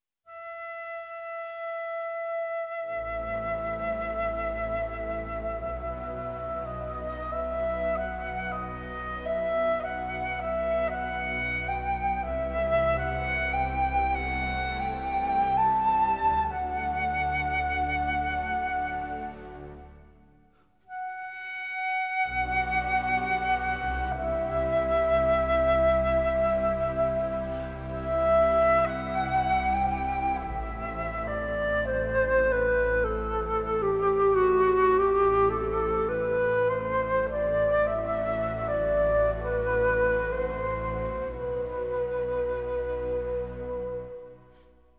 I performed five stages of sample rate conversion on an audio signal sampled at 11025 S/s resulting an an audio signal sampled at 8000 S/s. The spectral plots are shown below in dB scaling.